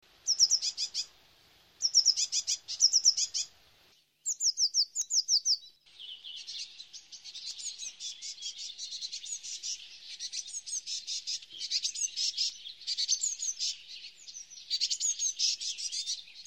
Pimpelmees
Pimpelmees.mp3